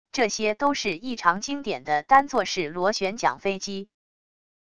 这些都是异常经典的单座式螺旋桨飞机wav音频